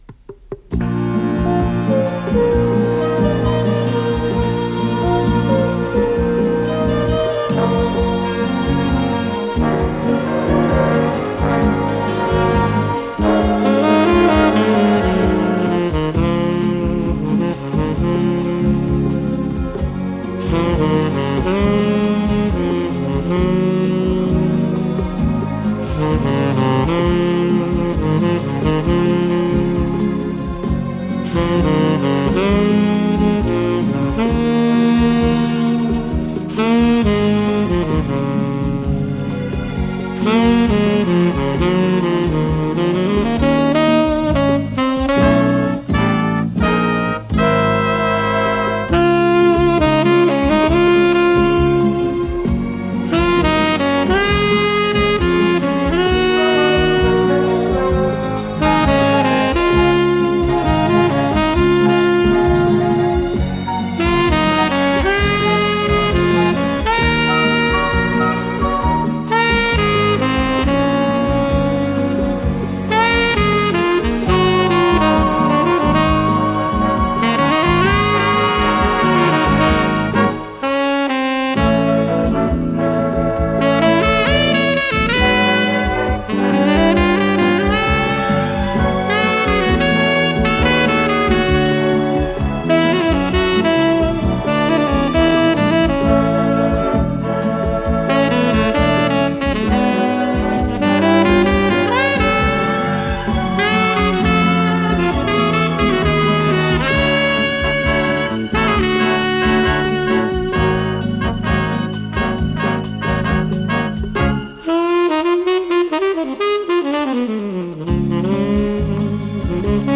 с профессиональной магнитной ленты
РедакцияМузыкальная
ВариантДубль моно